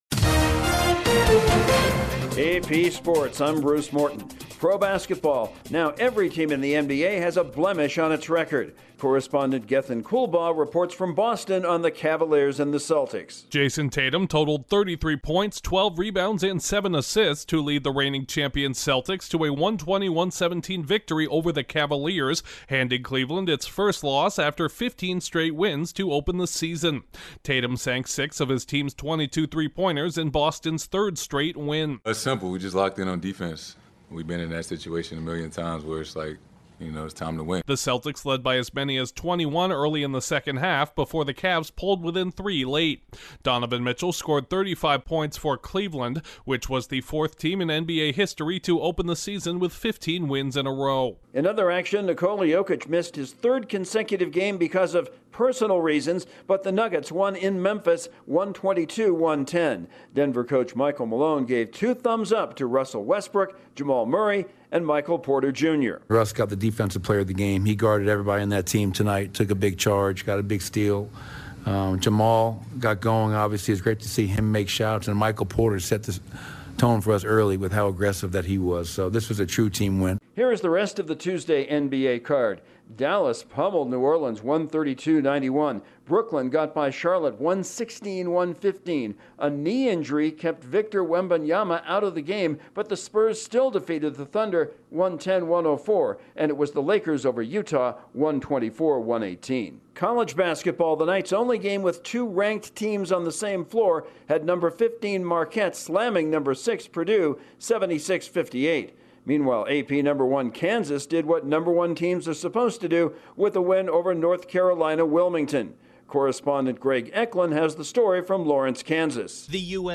The Cavaliers are no longer unbeaten, iarquette tops Purdue in college hoopes, the 2024 Major League managers of the year have been crowned and the NHL's Jets end their skid. Correspondent